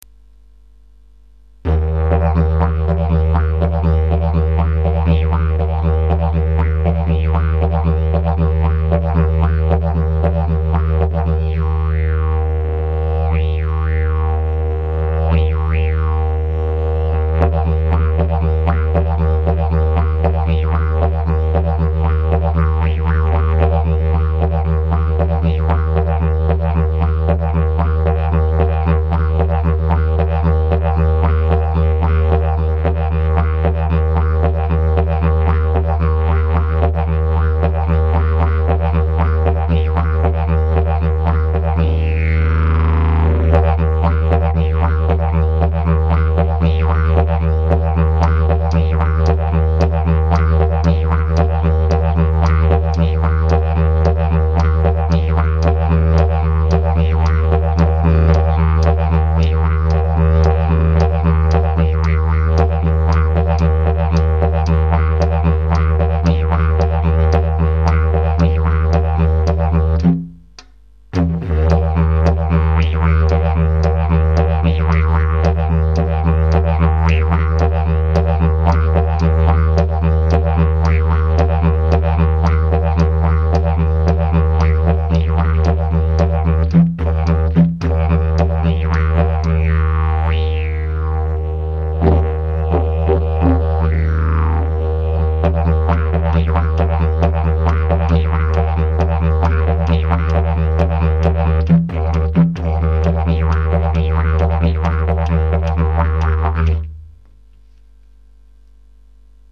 Superbe Didgeridoo ABORIGINAL ART a VENDRE note FA
la note FA/FA de 1,37m avec une cloche de 13cm embouchure extérieur 5cm et intérieur 2,8x3cm
Il est en bloowood tout epoxié de la grande tradition de chez Aboriginal art jamais fissuré et biensur termité
Bien péchu et un bon repondant!